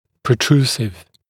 protrusive.mp3